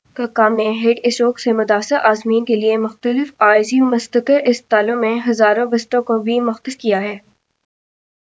Spoofed_TTS/Speaker_12/15.wav · CSALT/deepfake_detection_dataset_urdu at main